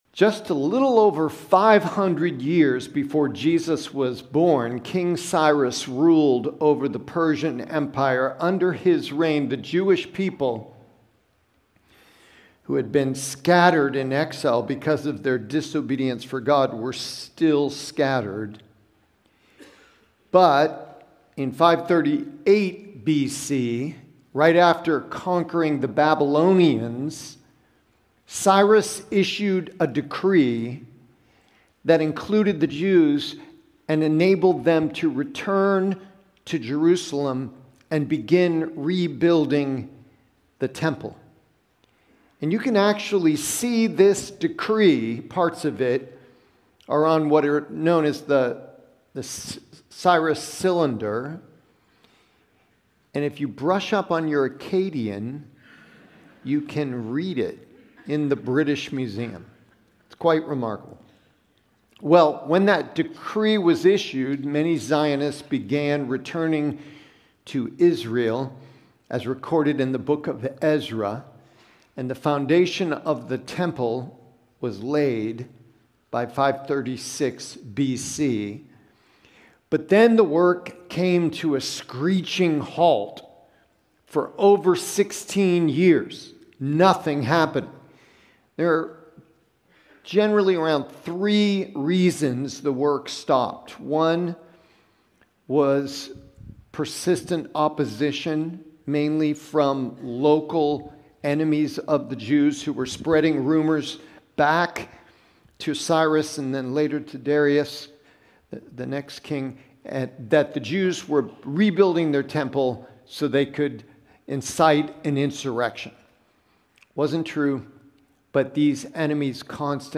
Prev Previous Sermon Next Sermon Next Title Little Faith